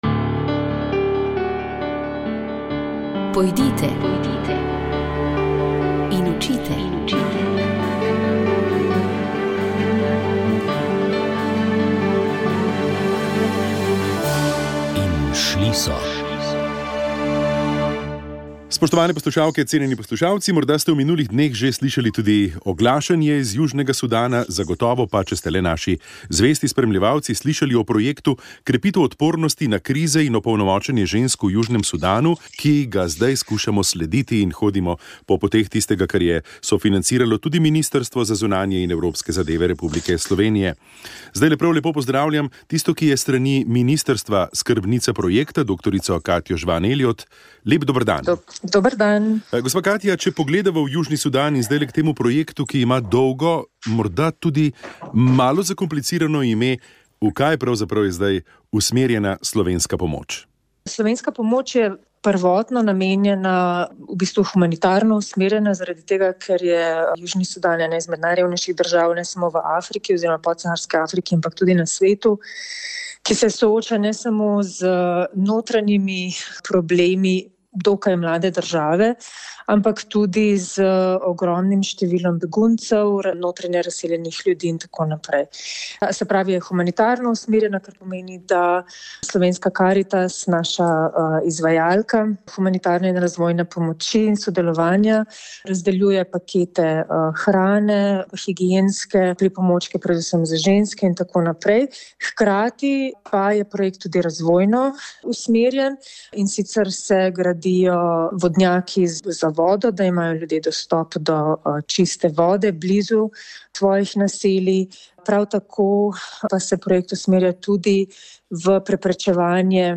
Slišali pa smo tudi dve pravljici.